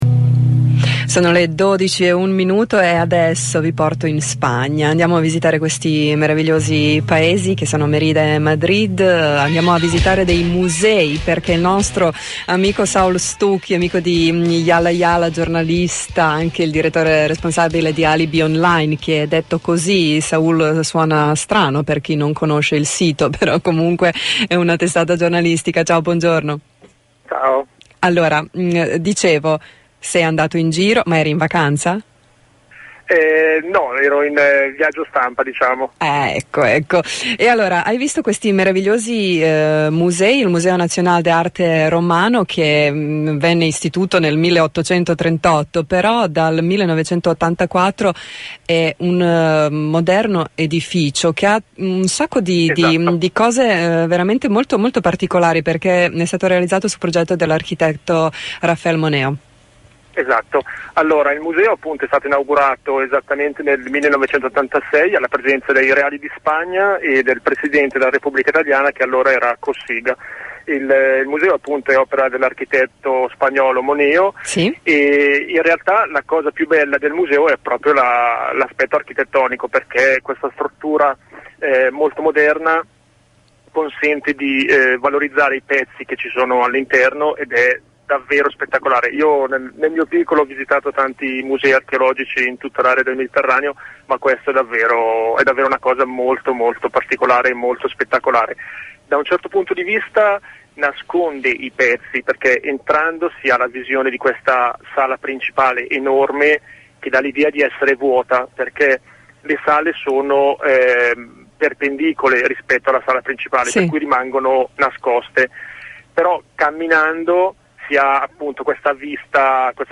in collegamento telefonico